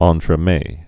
(ŏntrə-mā, -mĕ)